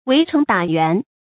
圍城打援 注音： ㄨㄟˊ ㄔㄥˊ ㄉㄚˇ ㄧㄨㄢˊ 讀音讀法： 意思解釋： 指一種戰術。